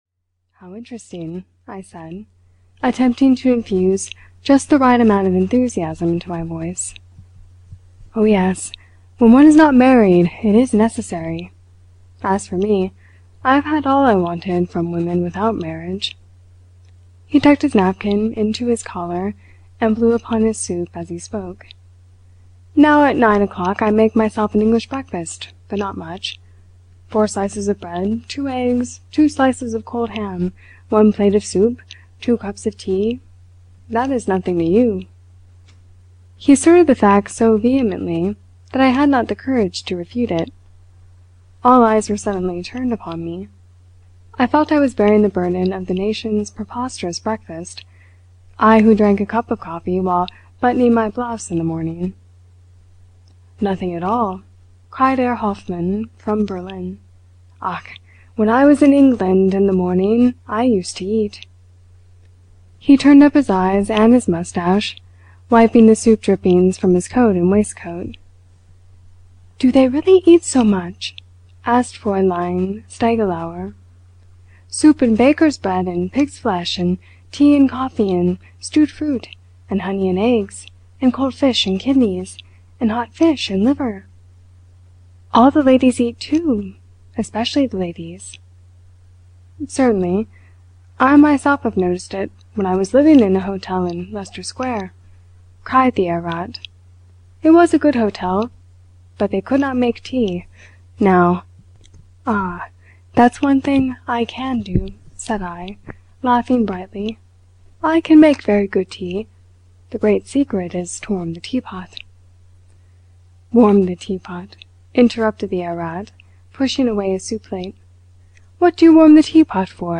In a German Pension (EN) audiokniha
Ukázka z knihy